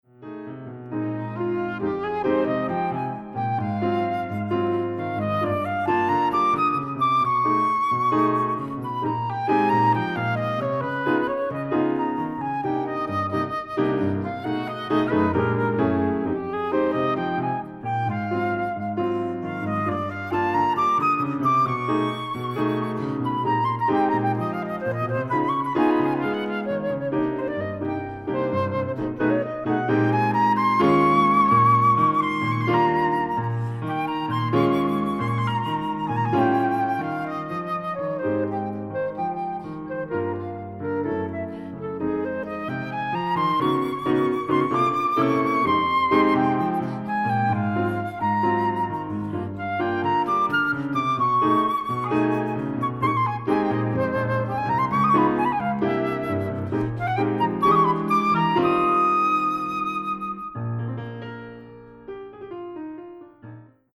Music for flute and piano
Classical